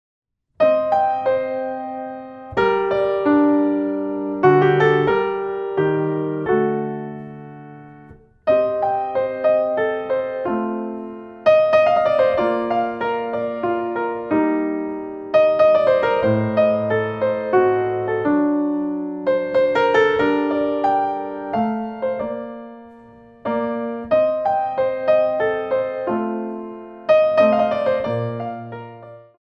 4 bar intro 3/4